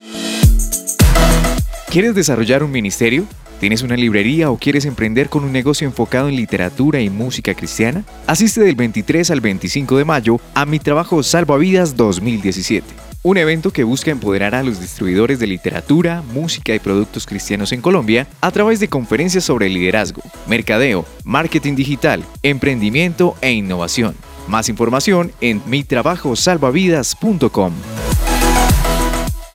Locutor Comercial de Bogota Colombia.
Sprechprobe: eLearning (Muttersprache):